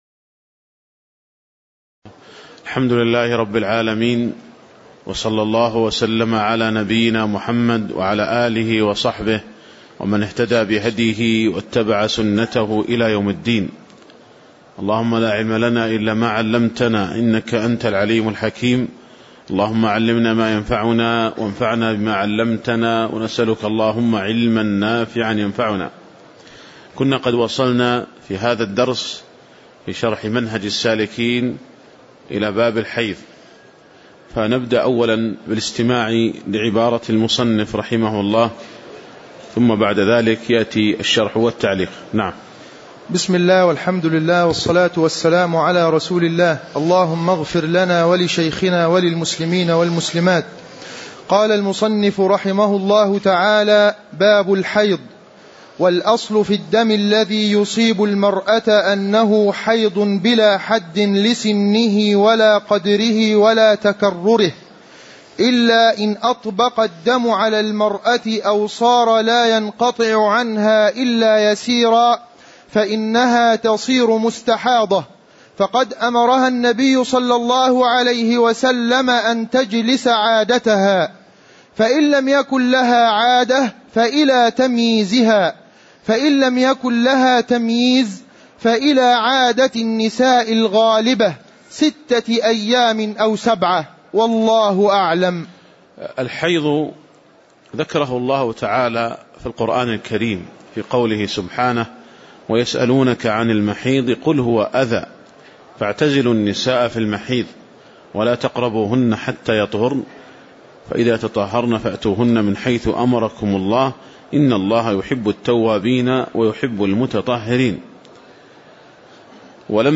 تاريخ النشر ١٥ صفر ١٤٣٨ هـ المكان: المسجد النبوي الشيخ